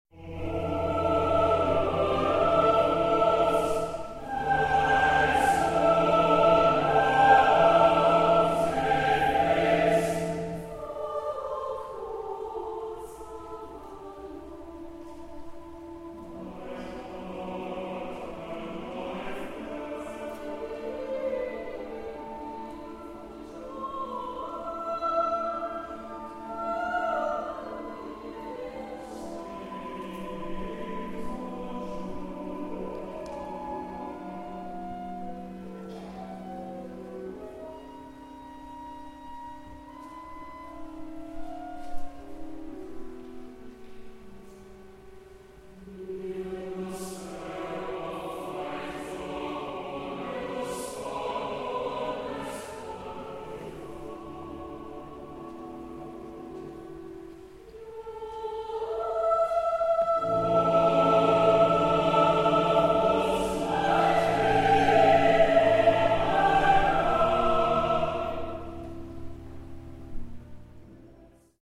Instrumentation: SATB choir and organ